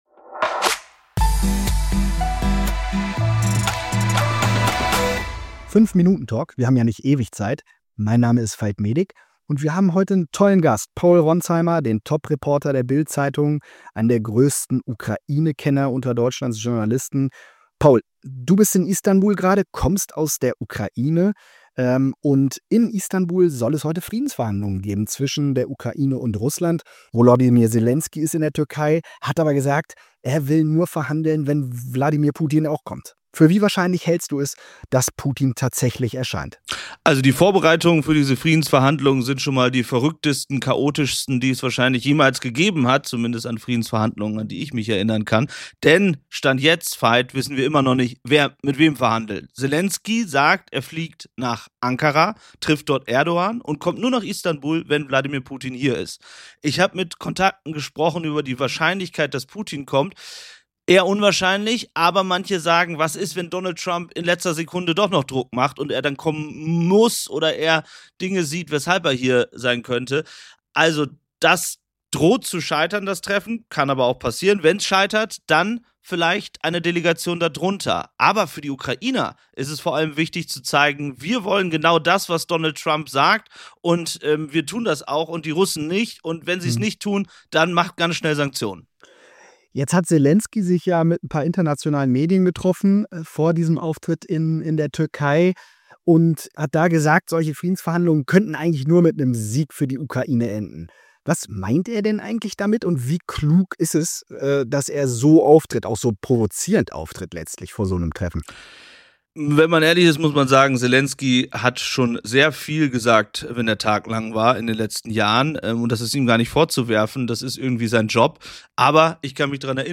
Kommt heute der Ukraine-Durchbruch? Der Talk mit Paul Ronzheimer